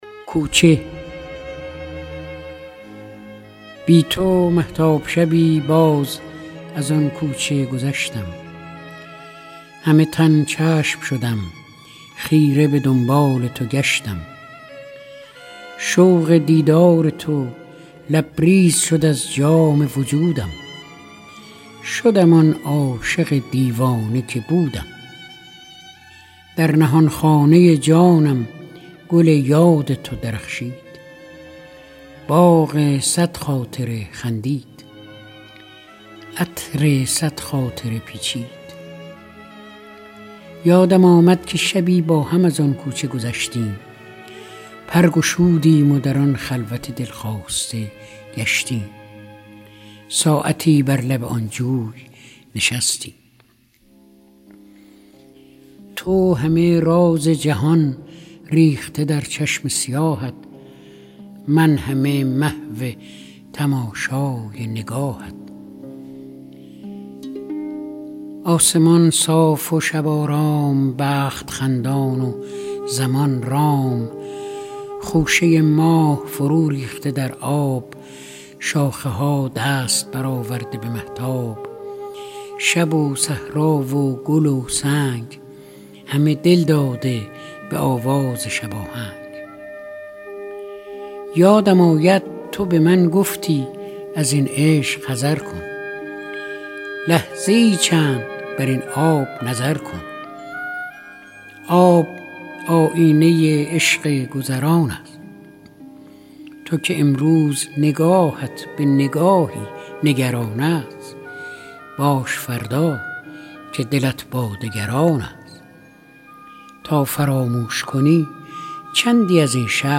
دانلود دکلمه کوچه با صدای فریدون مشیری
گوینده :   [فریدون مشیری]